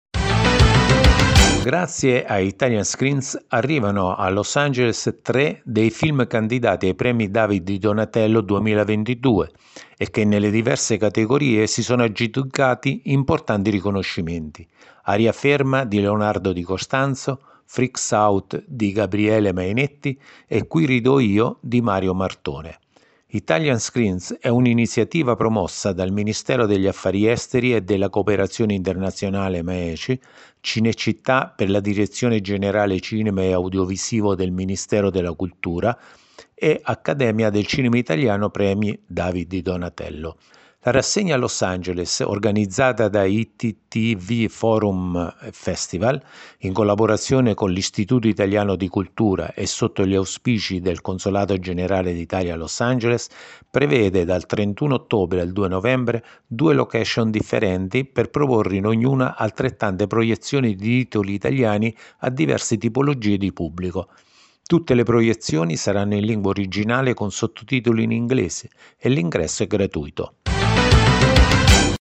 A Los Angeles il cinema italiano d’autore con “Italian Screens” (audio notizia)